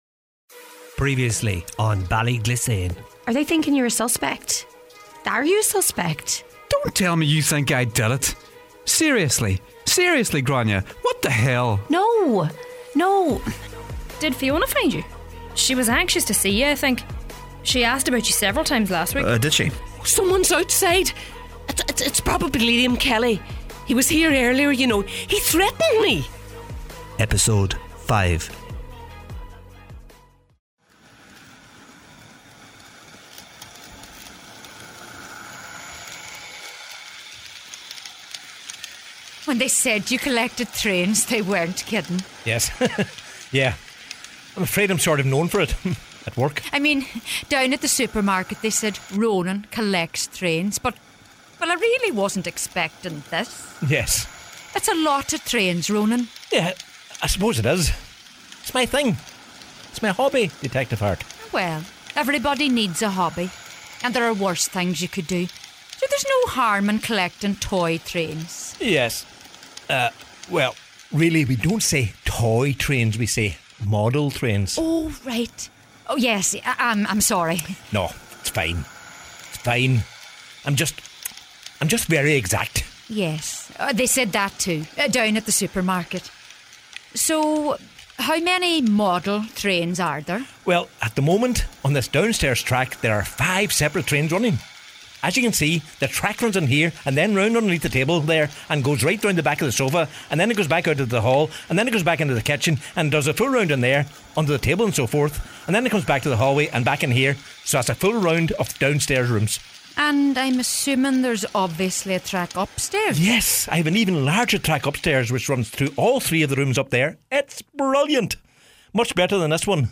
A drama series set in a small town somewhere in the heart of Donegal, where we meet various people who live in Ballyglissane and learn about their lives, their problems, and their secrets.